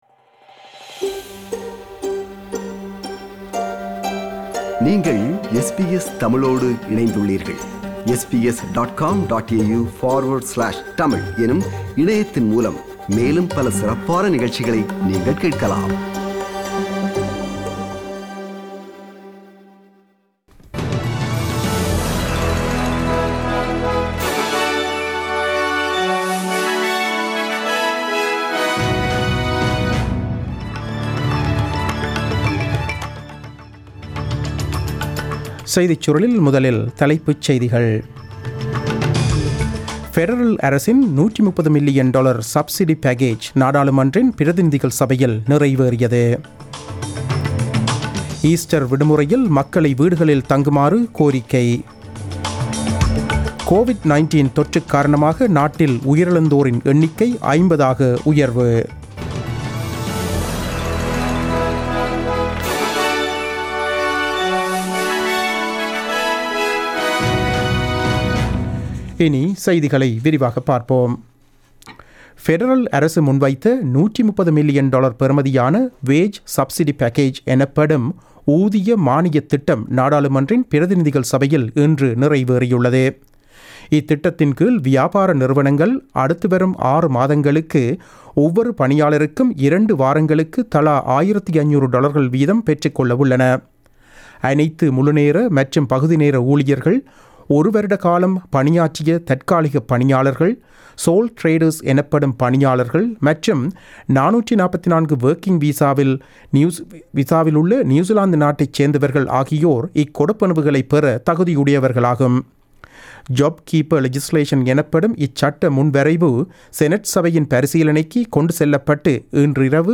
The news bulletin broadcasted on 08 April 2020 at 8pm.